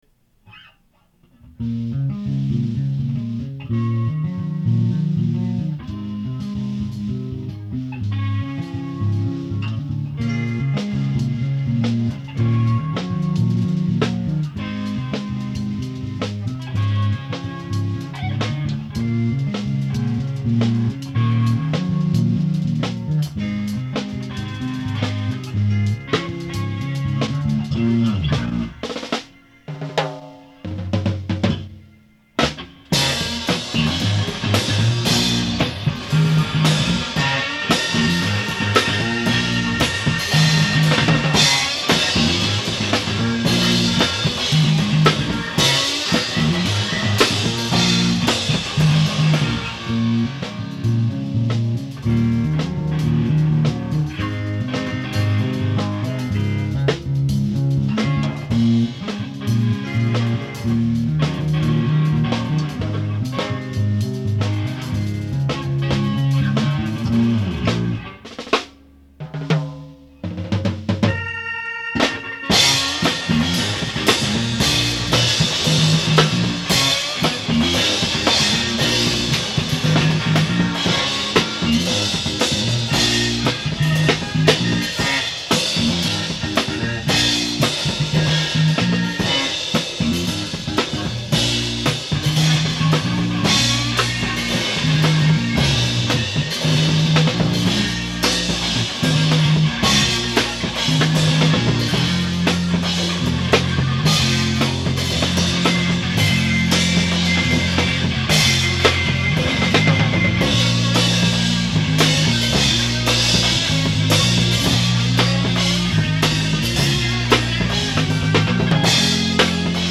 Curious Yellow, My High School Rock Band